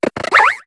Cri de Colombeau dans Pokémon HOME.